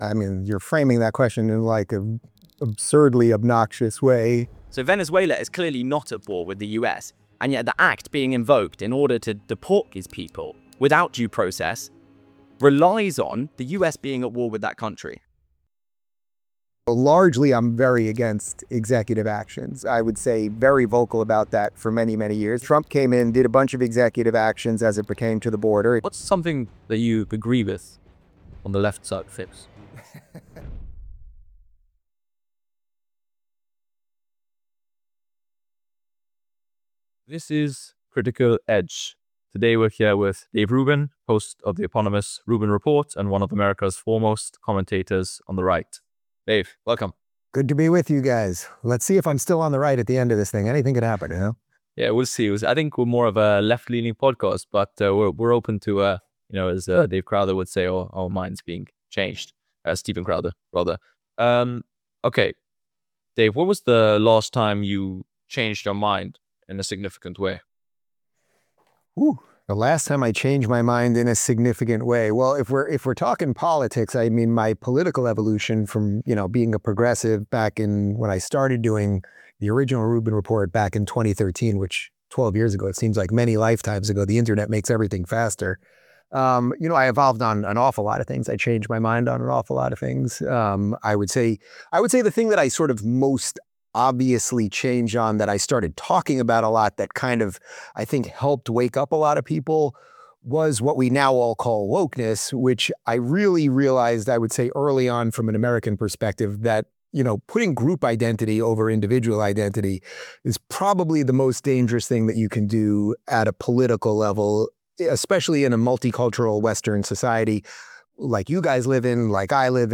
In this insightful interview, she delves into the quarter-life crisis, choice overload, and the power of embracing solitude.